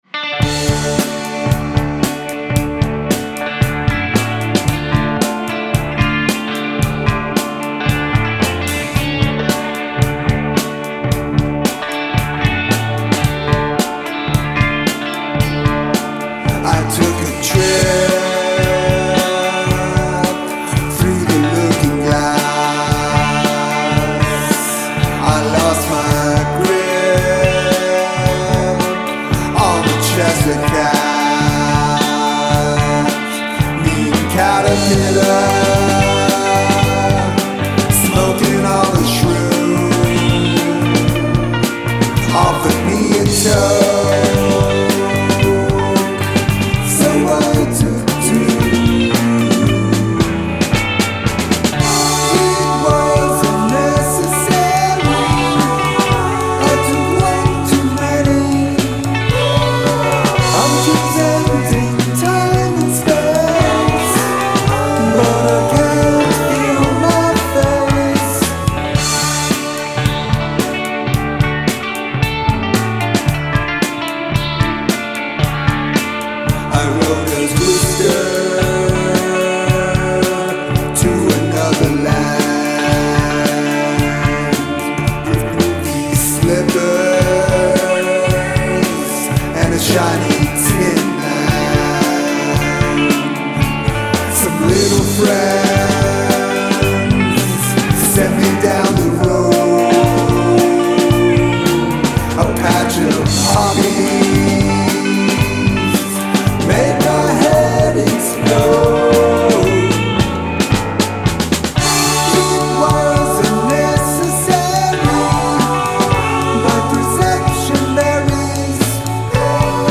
Psychedelic